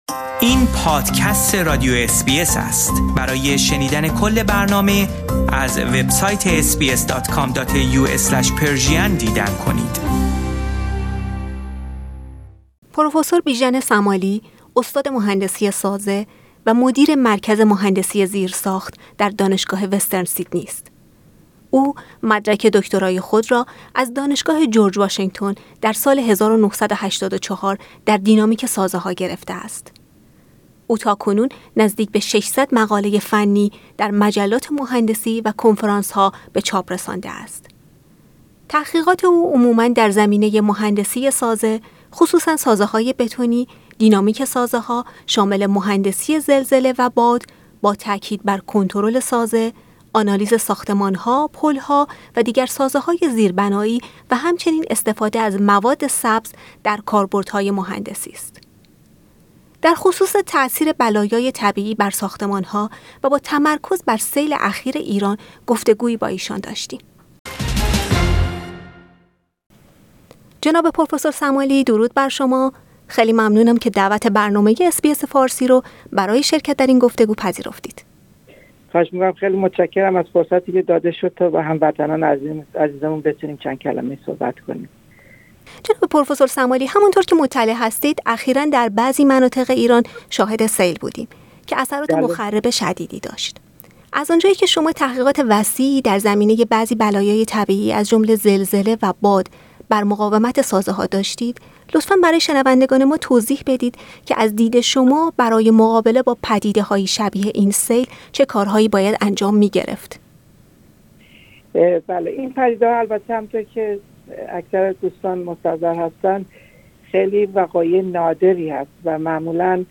در خصوص تاثیر بلایای طبیعی بر ساختمان ها و با تمرکز بر سیل اخیر ایران گفتگویی با او داریم.